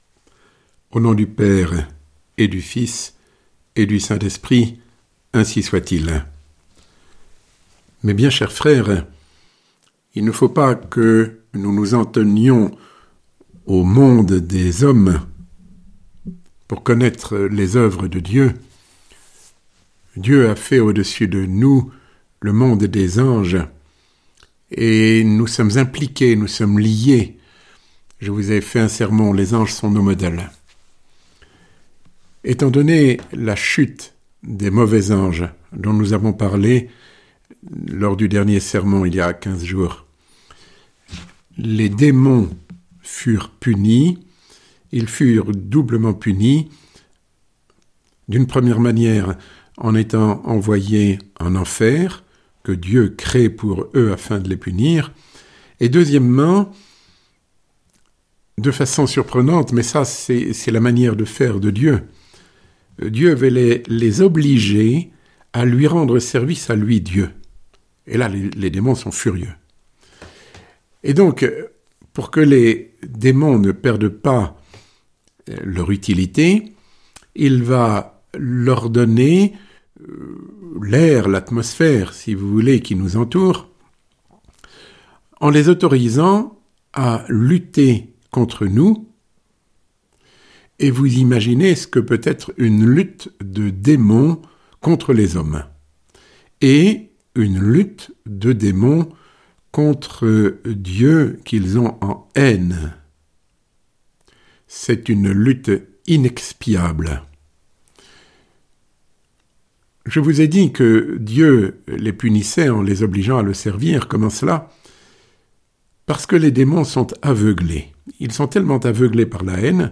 Sermon Les œuvres de Dieu ~ 7 Les bons anges dans la lutte à nos côtés ~ L’archange Raphaël et Tobie